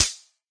plasticmetal3.ogg